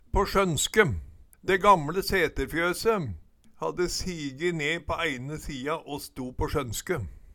på sjønske - Numedalsmål (en-US)